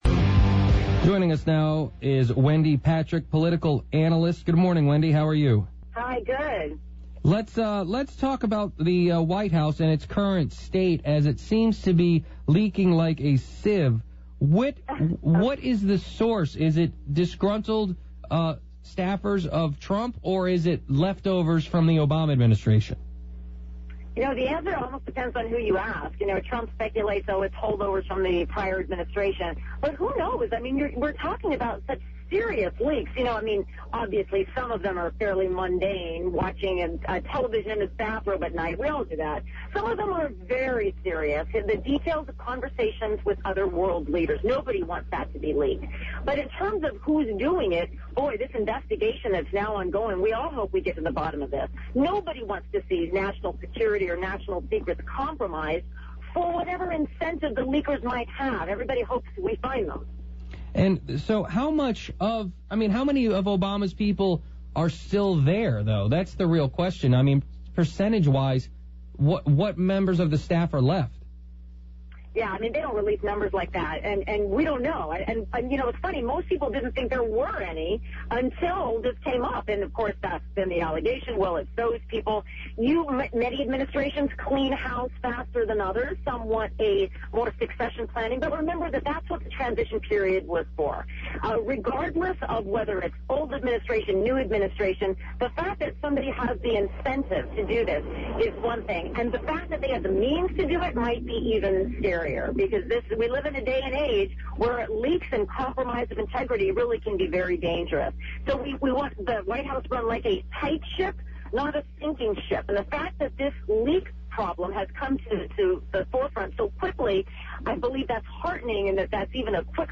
Not really but one caller accuses of supporting terrorists. PLUS - Lots of interviews about White House leaks, cell phone addiction and Trump's day in Court.